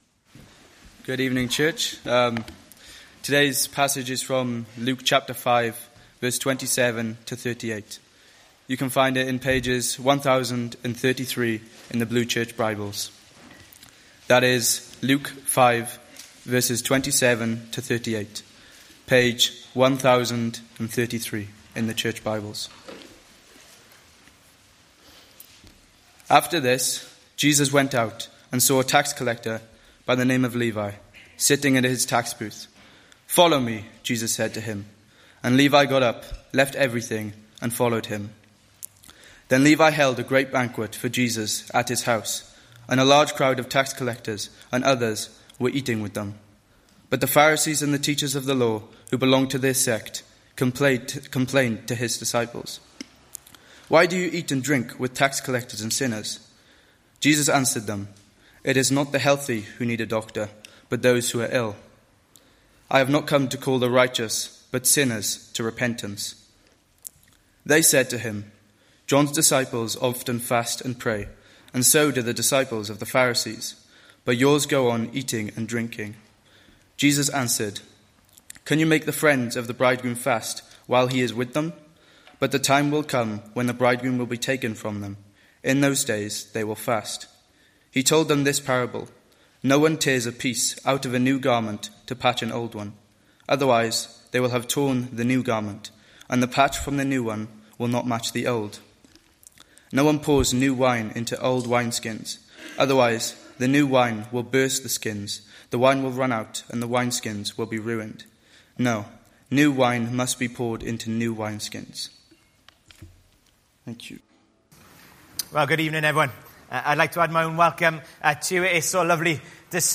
Luke 5:27-39; 01 June 2025, Evening Service.